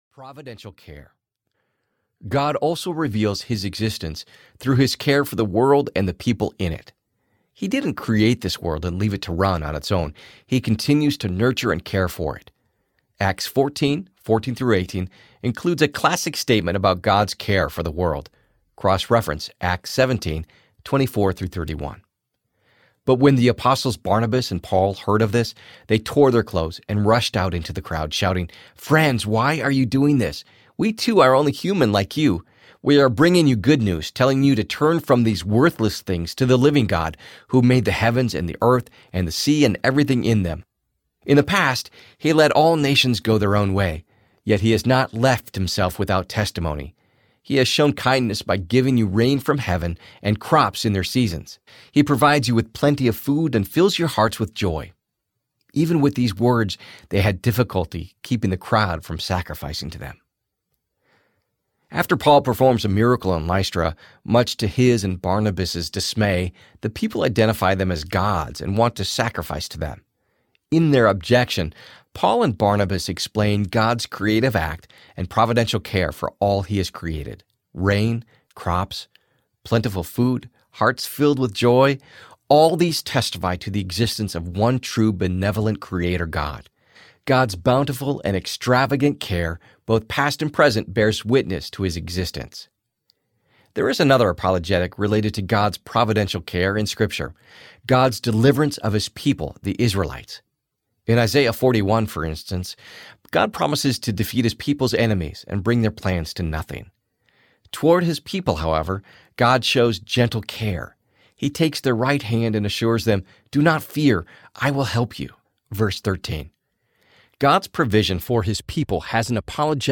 Apologetics at the Cross Audiobook
Conversational in tone and balanced in approach, Apologetics at the Cross provides a listenable introduction to the field of apologetics.
Narrator
9.25 Hrs. – Unabridged